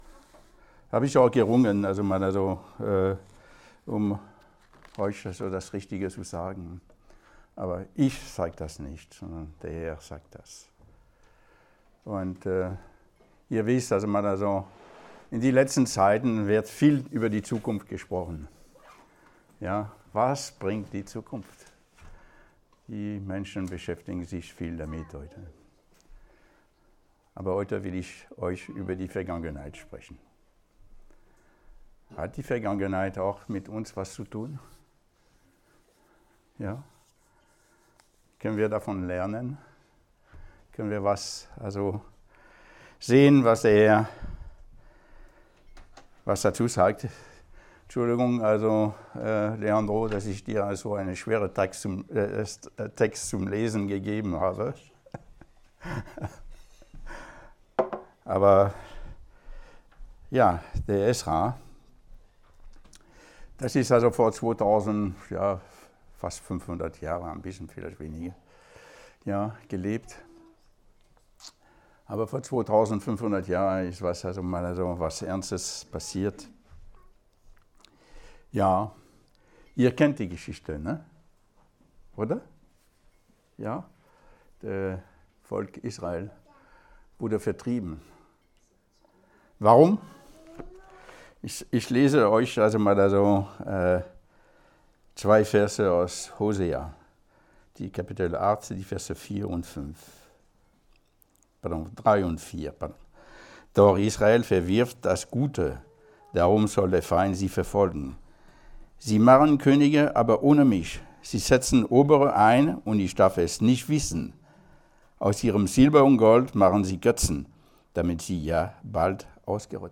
Gottesdienst am 03.05.2020
Könige 17 Download Now Veröffentlicht in Predigten und verschlagwortet mit 03.05.2020 , 1.